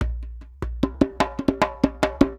Index of /90_sSampleCDs/USB Soundscan vol.36 - Percussion Loops [AKAI] 1CD/Partition A/19-100JEMBE
100 JEMBE6.wav